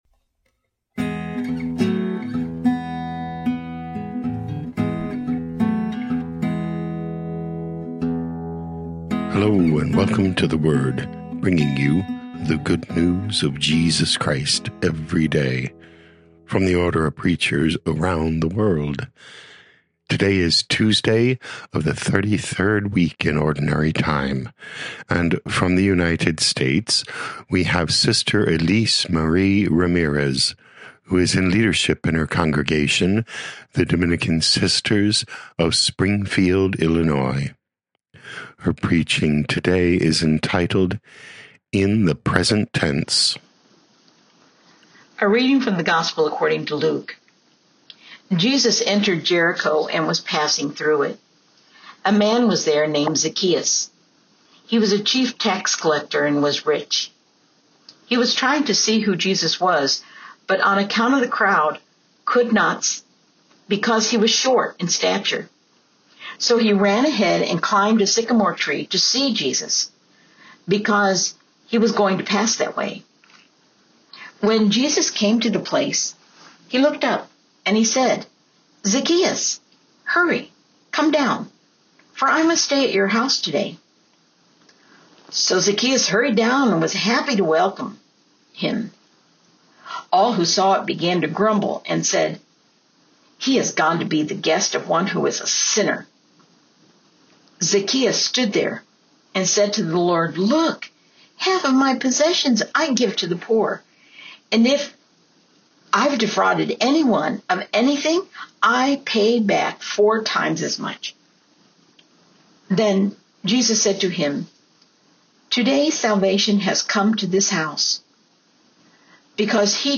O.P. Preaching